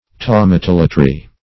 Thaumatolatry \Thau`ma*tol"a*try\
(th[add]`m[.a]*t[o^]l"[.a]*tr[y^]), n. [Gr. qay^ma,